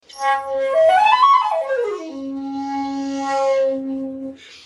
Shakuhachi 50